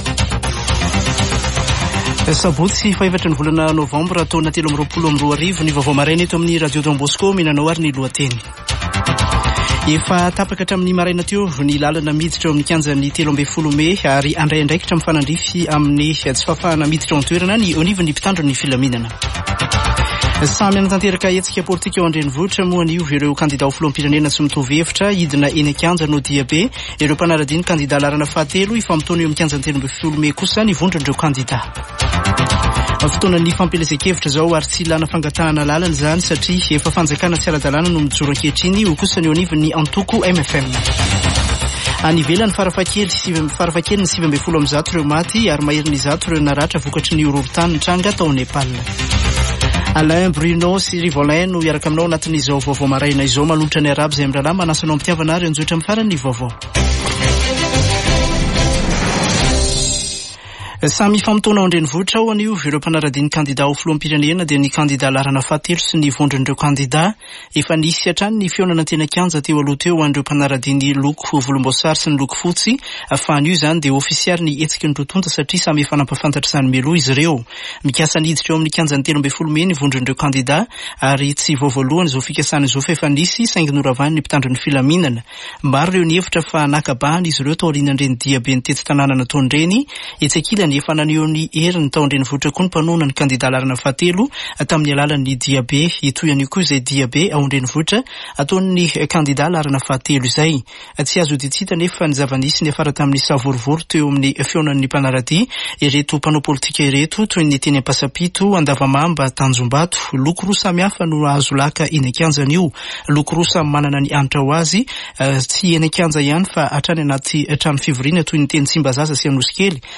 [Vaovao maraina] Sabotsy 4 nôvambra 2023